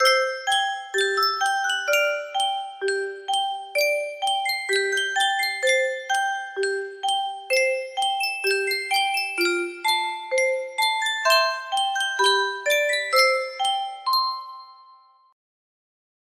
Sankyo Music Box - Just a Closer Walk With Thee THD music box melody
Full range 60